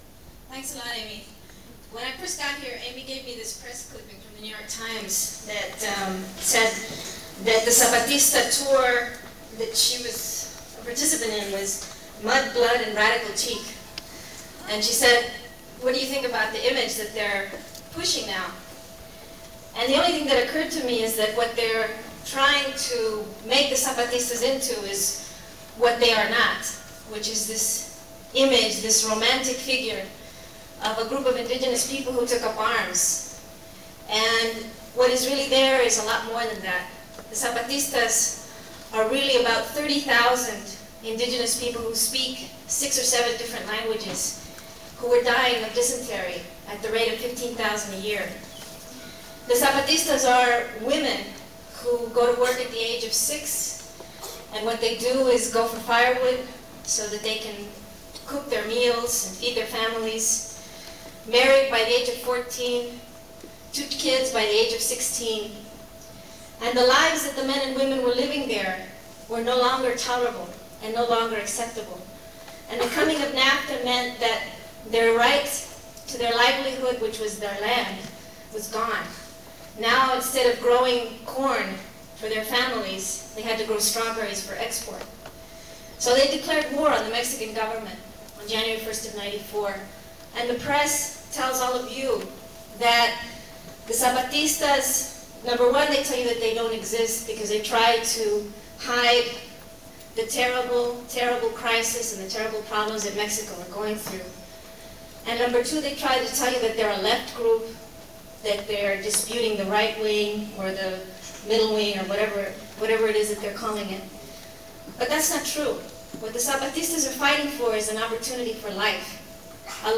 lifeblood: bootlegs: 1996-08-24: las cruces, new mexico